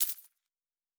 Coins 06.wav